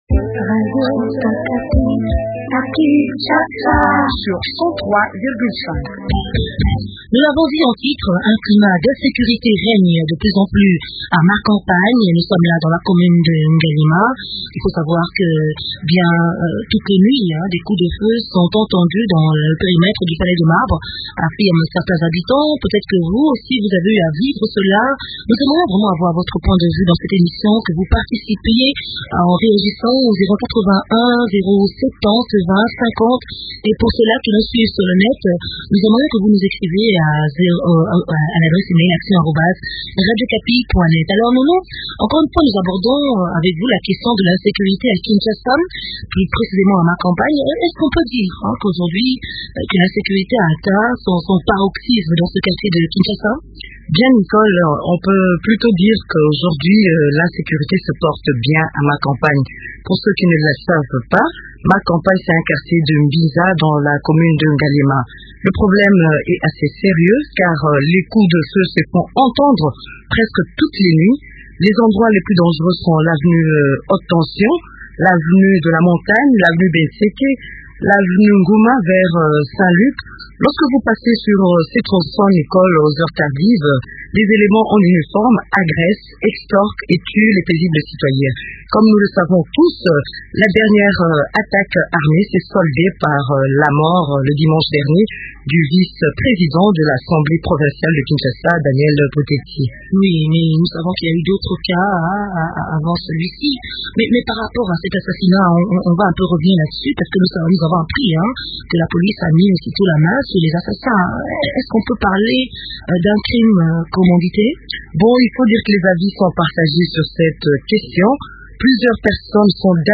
font le point avec Colonel KANYAMA, commandant de la police dans le district de Lukunga.